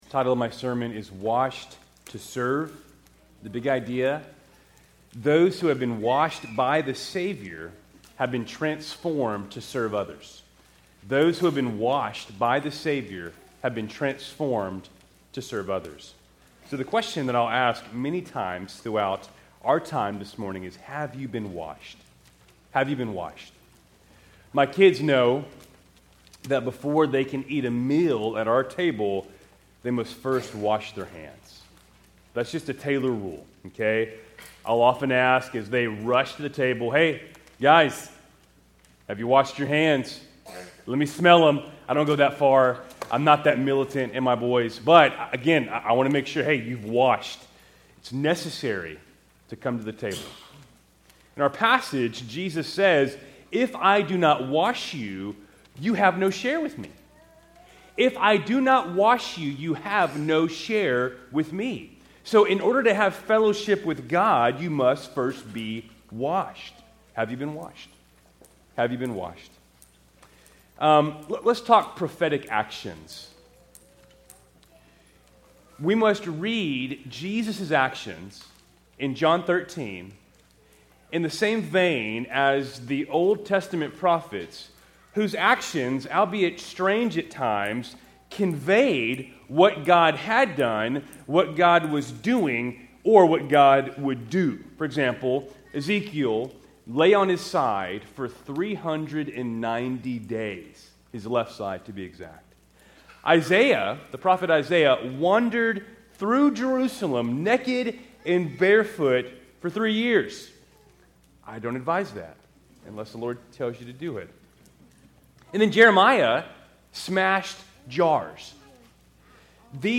Keltys Worship Service, June 15, 2025 Father’s Day